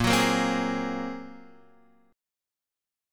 A#7sus2#5 Chord